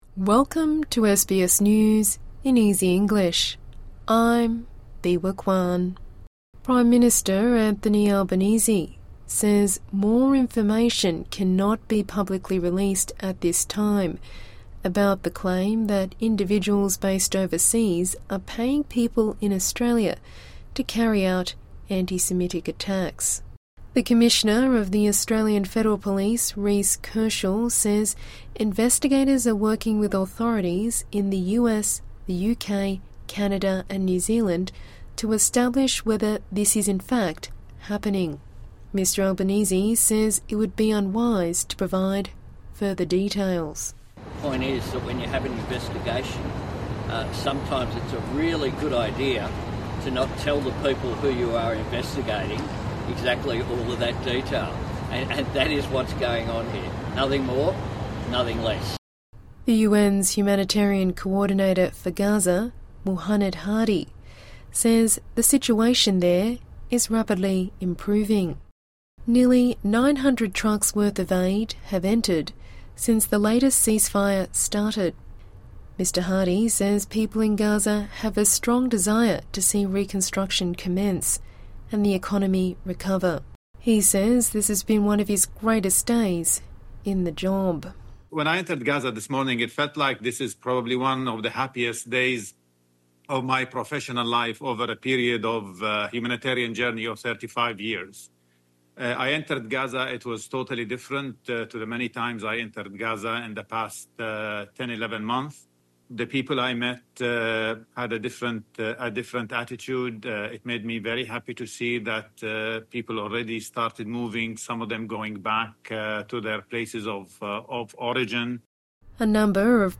A daily 5-minute news wrap for English learners and people with disability.